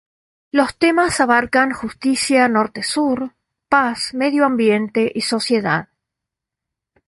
te‧mas
Pronúnciase como (IPA)
/ˈtemas/